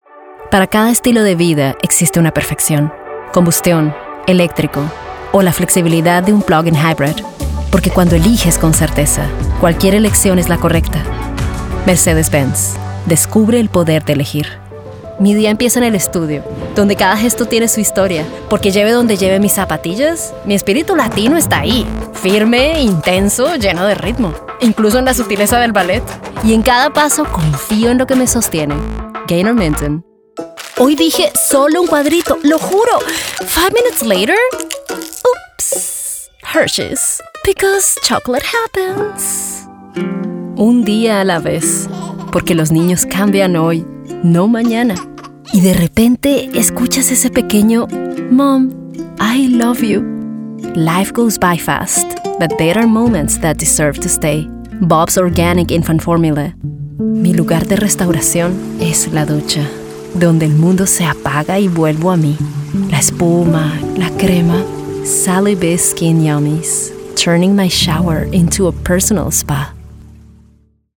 Spanish Commercial Demo